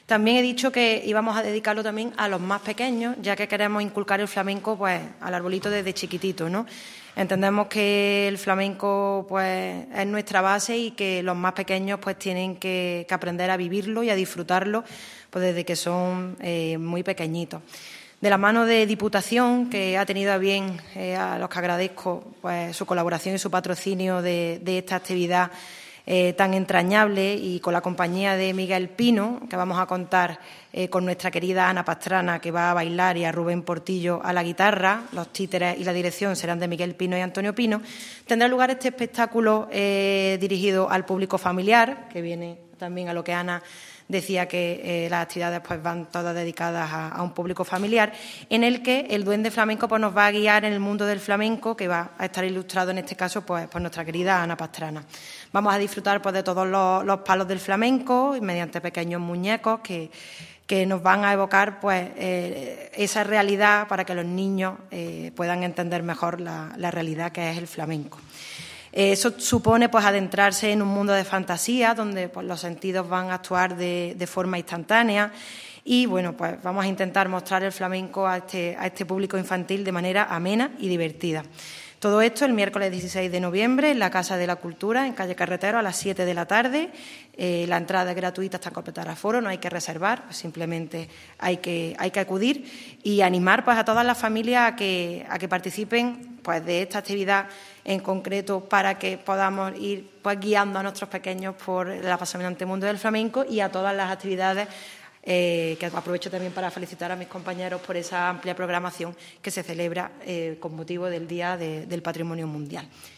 El teniente de alcalde delegado de Patrimonio Mundial, Juan Rosas, la teniente de alcalde de Turismo y Patrimonio Histórico, Ana Cebrián, y la teniente de alcalde de Cultura y Tradiciones, Elena Melero, han informado en rueda de prensa del programa de actividades que el Ayuntamiento de Antequera promueve para conmemorar en nuestra ciudad la celebración tanto del Día Internacional del Patrimonio Mundial como del Día Internacional del Flamenco en torno al 16 de noviembre.
Cortes de voz